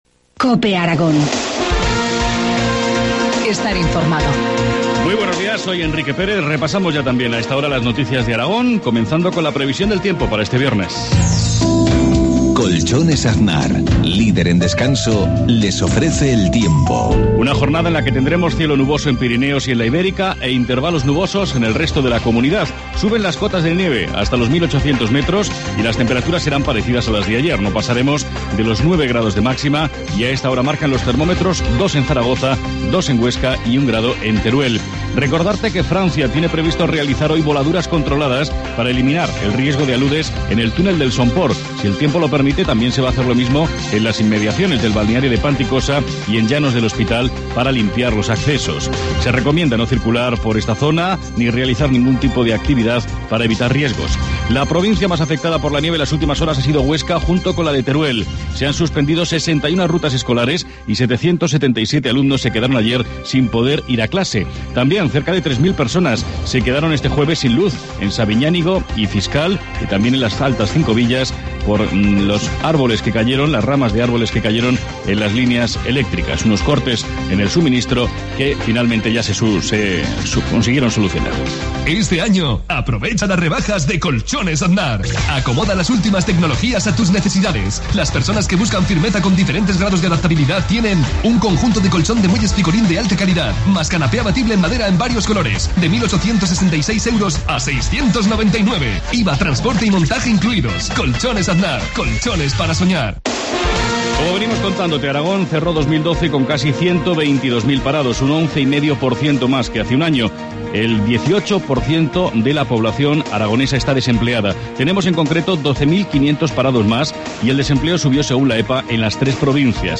Informativo matinal, viernes 25 de enero, 7.53 horas